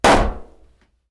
Balloon-Burst-04
balloon burst pop sound effect free sound royalty free Sound Effects